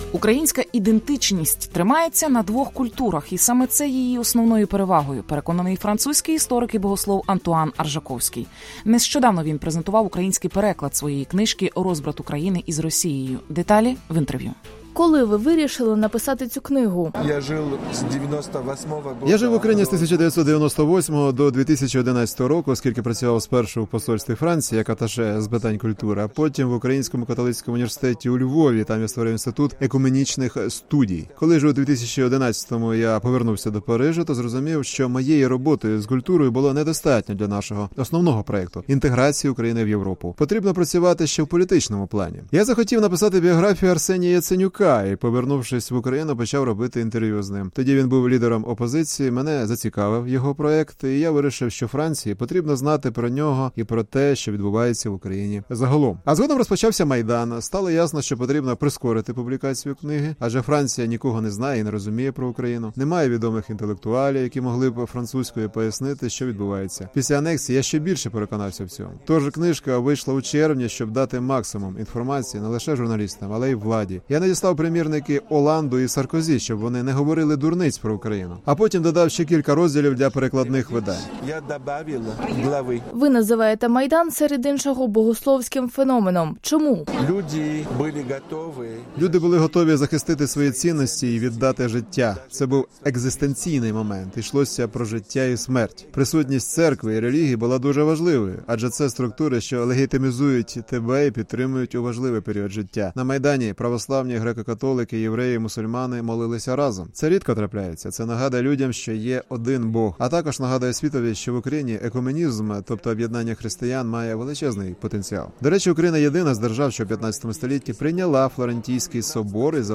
відео Радіо Свобода